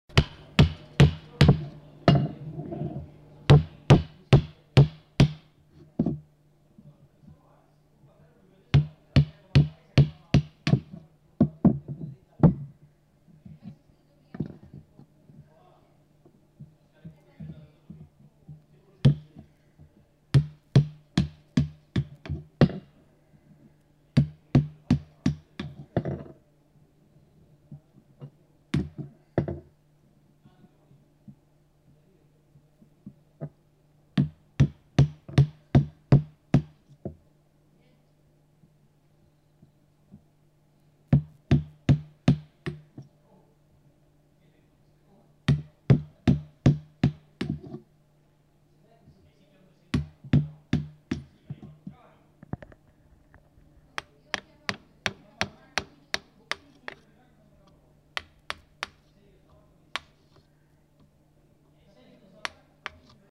Bruits de travail du bois
Lieu : [sans lieu] ; Gers
Genre : paysage sonore